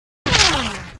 bullet.ogg